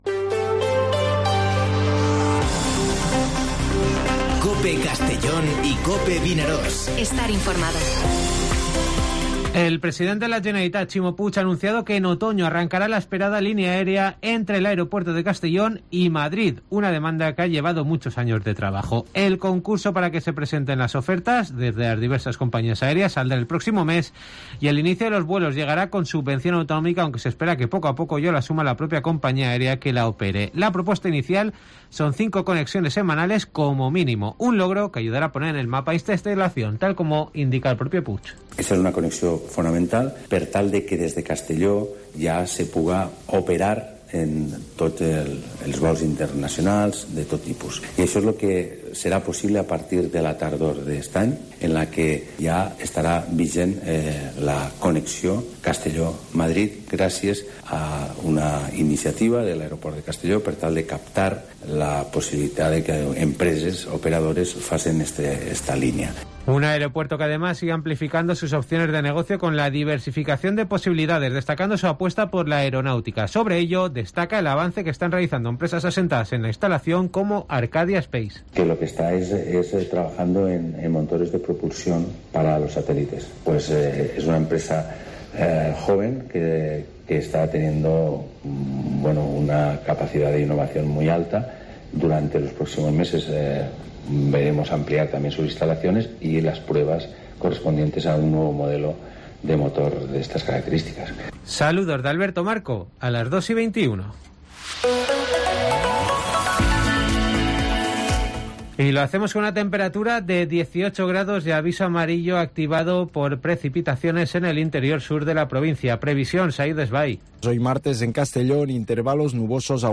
Informativo Mediodía COPE en Castellón (19/04/2022)